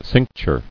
[cinc·ture]